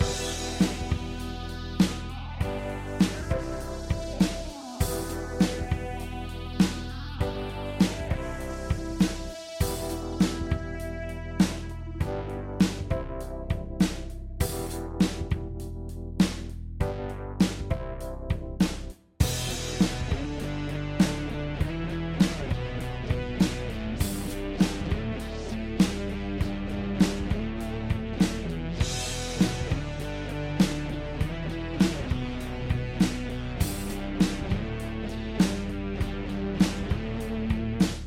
After normalization. It was purposefully left Keyboard and Guitar with no bass.